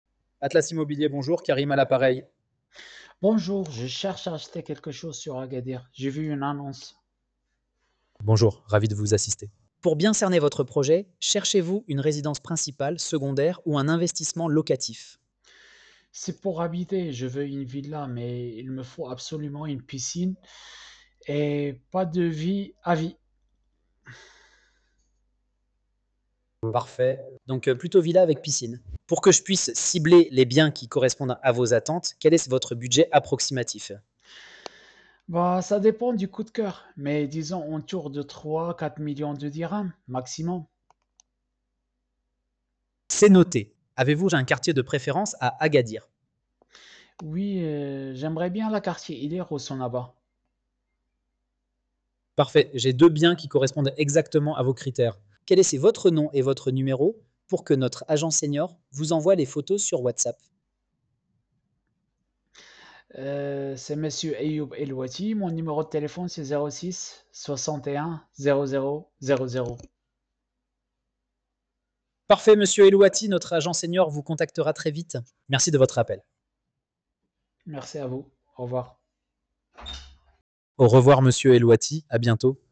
Écoutez notre IA.
Audio-pour-la-conversation-IMMOBILIERE.mp3